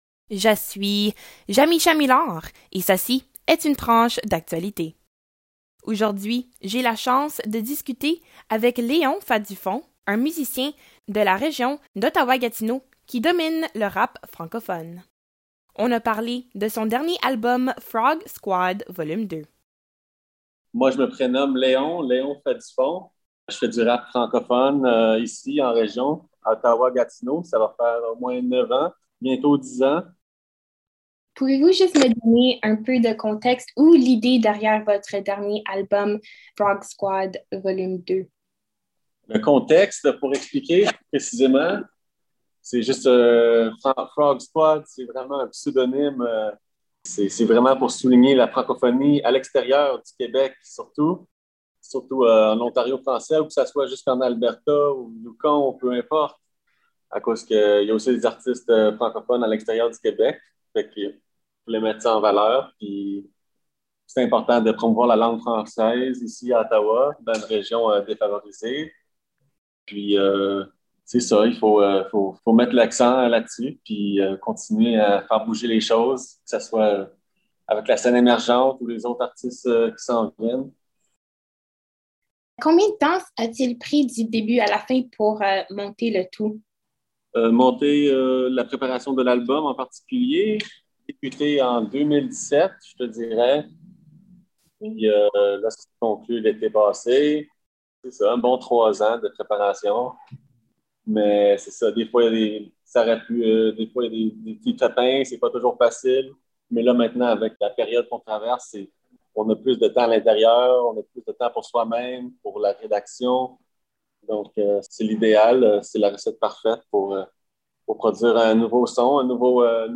FROG-SQUAD-VOL-2-FULL-ENTREVUE-CHANSON.mp3